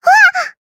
Taily-Vox-Sur1_jp.wav